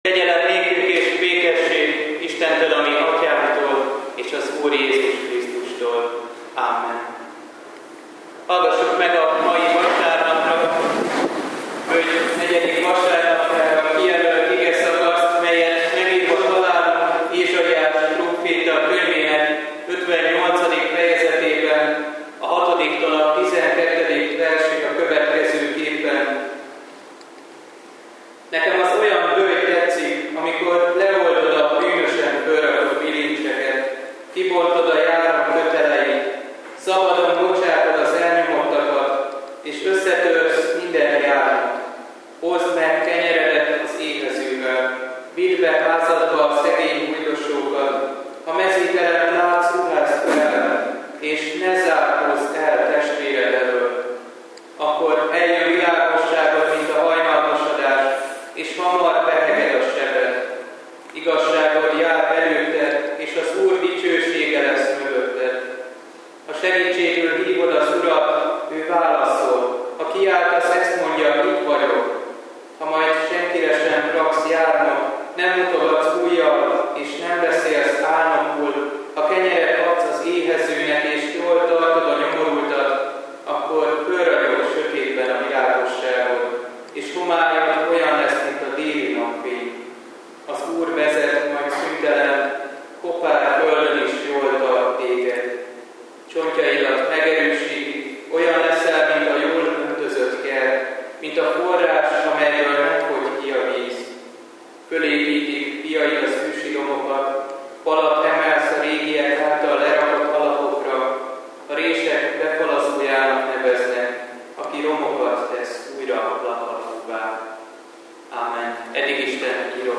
Böjt 4. vasárnapja, szupplikációi istentisztelet